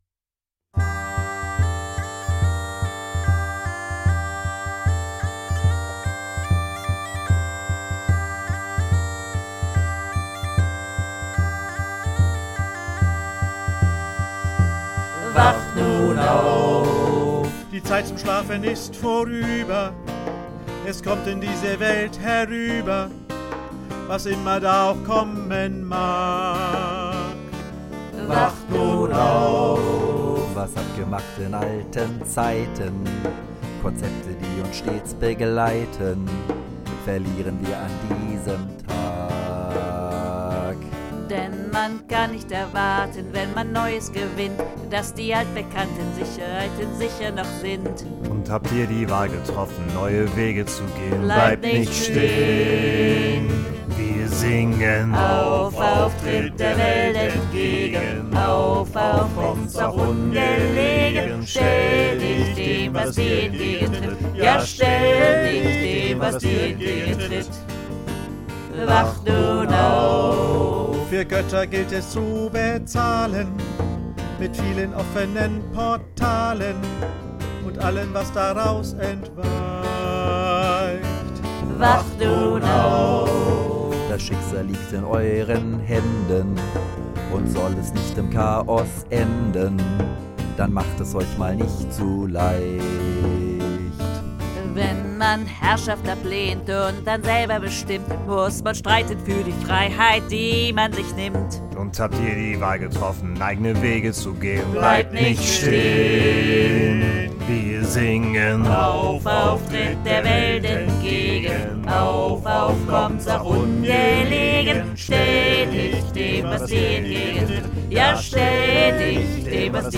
Tenor
Dudelsack